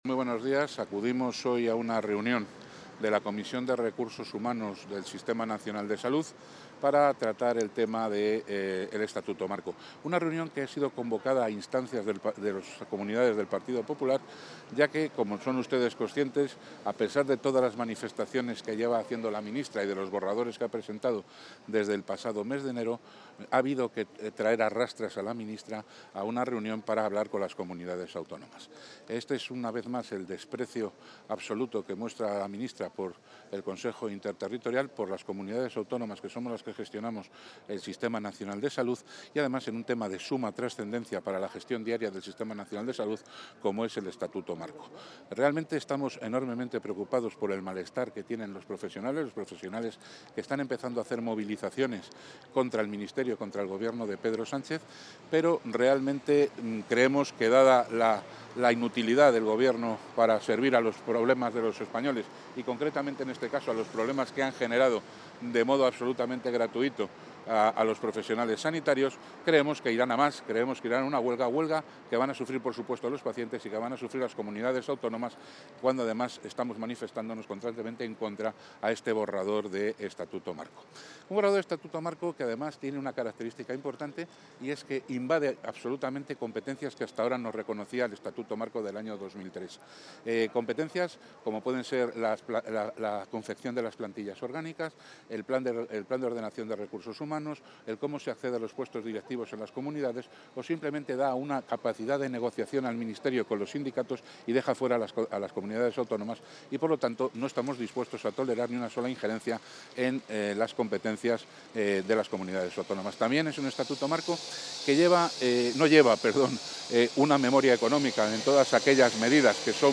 Declaraciones del consejero de Sanidad previas a la celebración de la Comisión de Recursos Humanos del Sistema Nacional de Salud